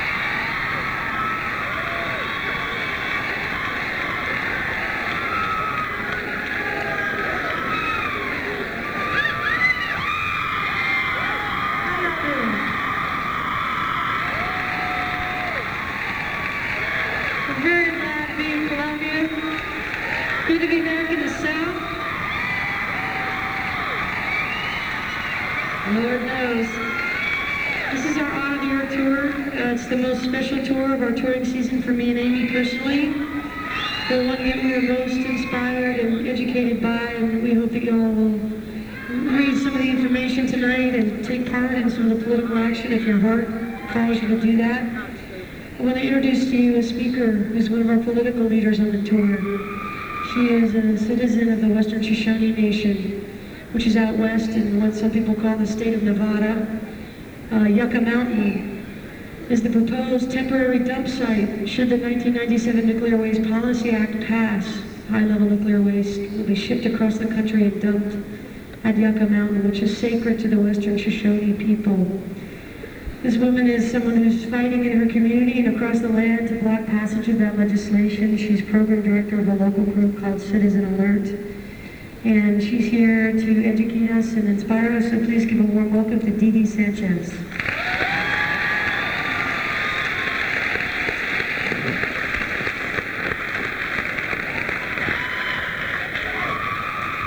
lifeblood: bootlegs: 1997-09-21: township auditorium - columbia, south carolina
01. introduction by emily saliers (1:37)